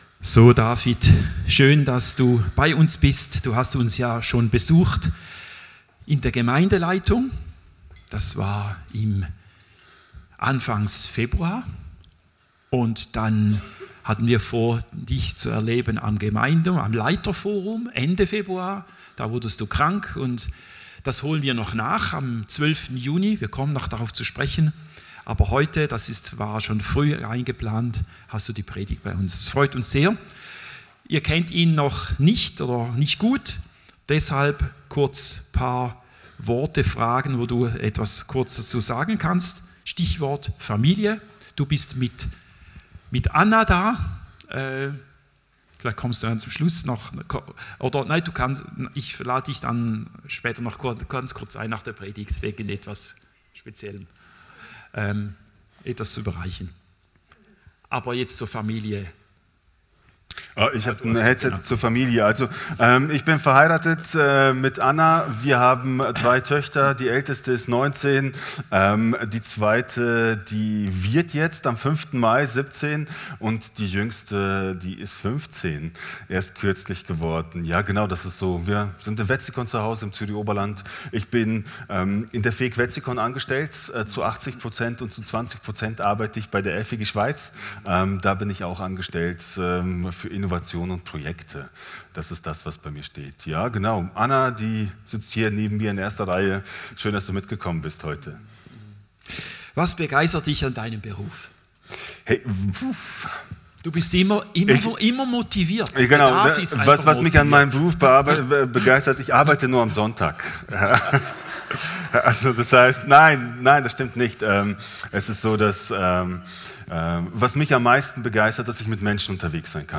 Predigt 19.